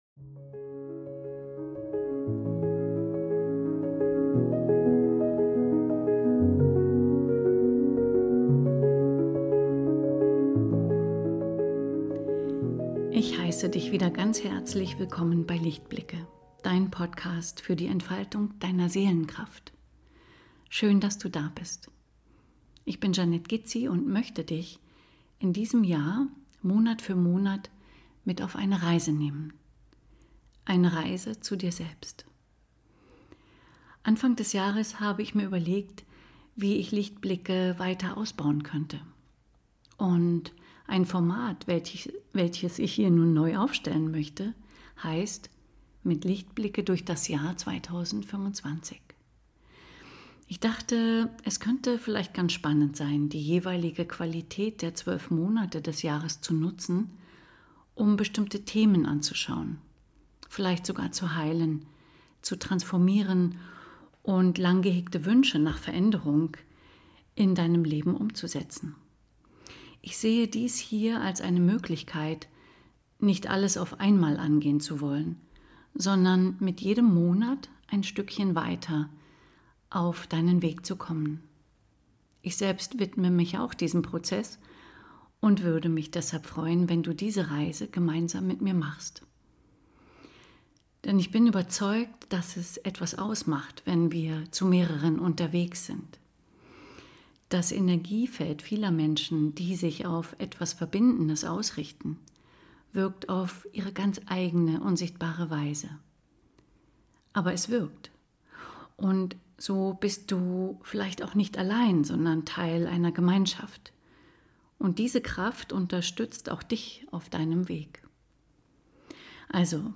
In einer geführten Meditation (ab Minute 8:56) lade ich dich zu einem Perspektivwechsel in Bezug auf ein Thema in deinem Leben ein, das du gerne in den Wandel und in die Heilung bringen möchtest.